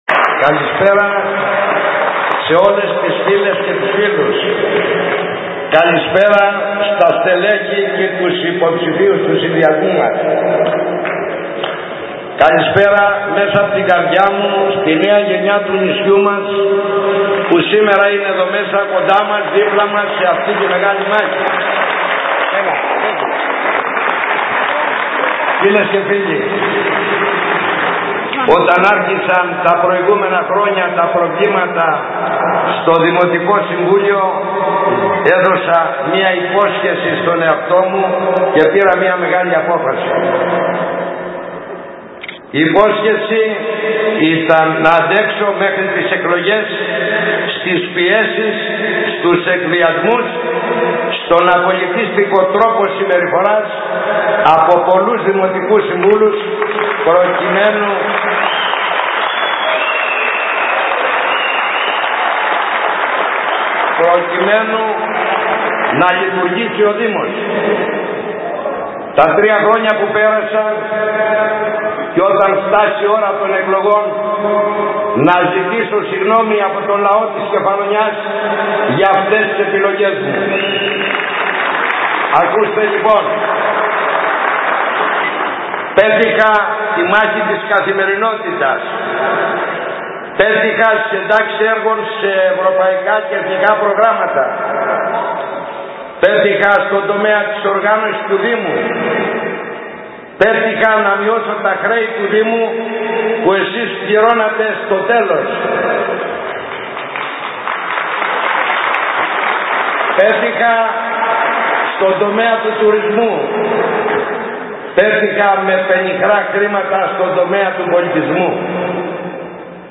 Σε ένα κατάμεστο «Κλειστό Γήπεδο» ο Αλέξανδρος Παρίσης παρουσίασε μέσα σε επευφημίες και χειροκροτήματα το ψηφοδέλτιο της ΙΣΧΥΡΗΣ ΚΕΦΑΛΟΝΙΑΣ.
ΟΜΙΛΙΑ-ΑΛ.-ΠΑΡΙΣΗ.mp3